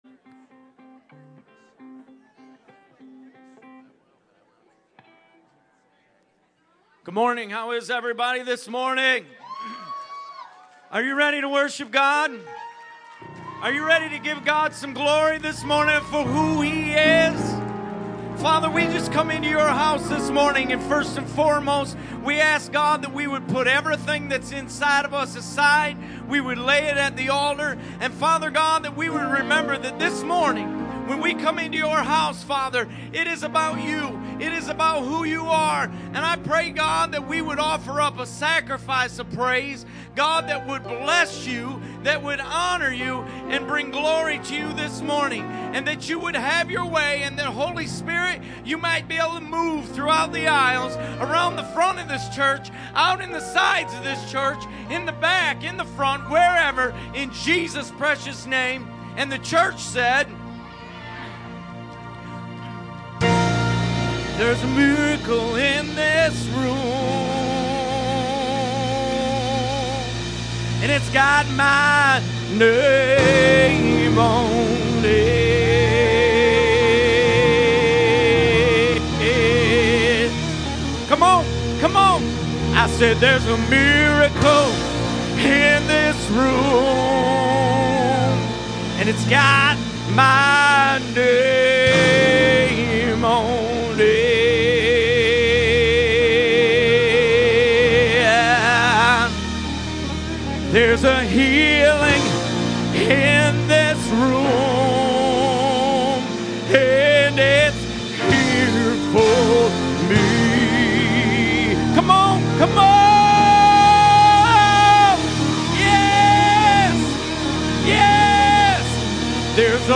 FATHER DESIRES TO..... 1ST SERVICE